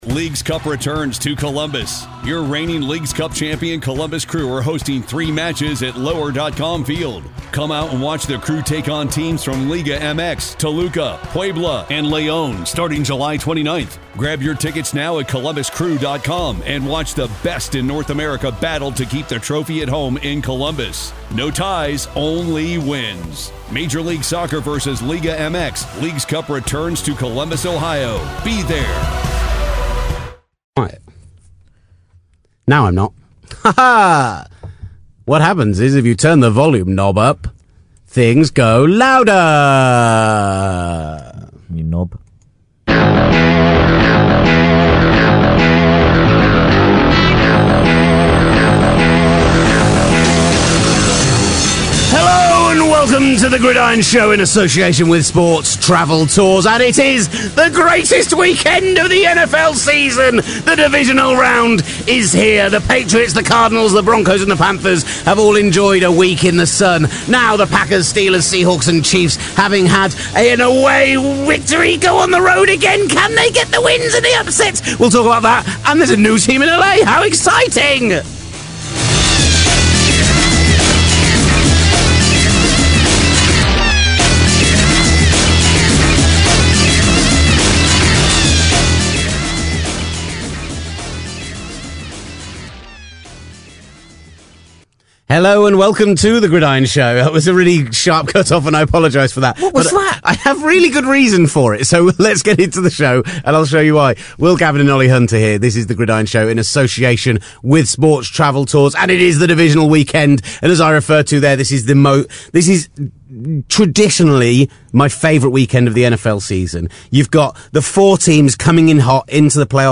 CBS's NFL Insider and top bloke Jason La Canfora joins us on the phone to break down the decision to bring home the LA Rams, and focus on some of those Head coaching decisions - good & bad. Plus we preview the weekend's divisional games, find a new favourite song for the show and JLC helps us give a new nickname.